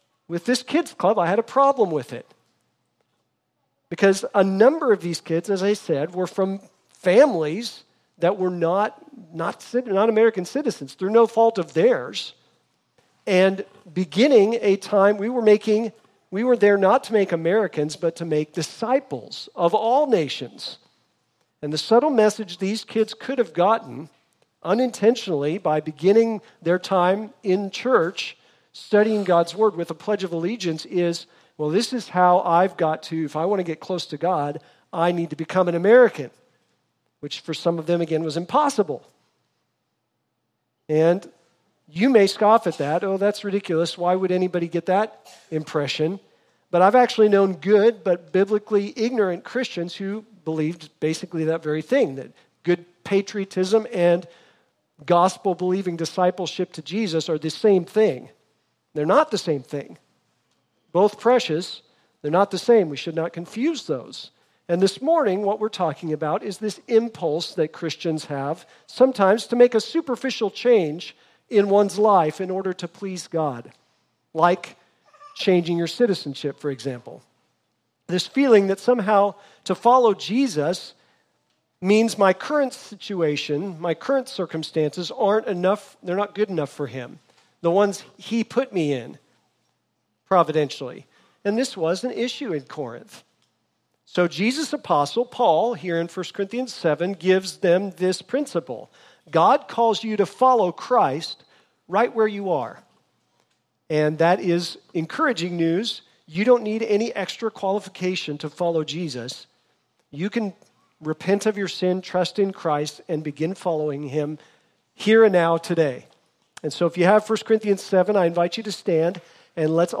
Sermons | Redemption Bible Church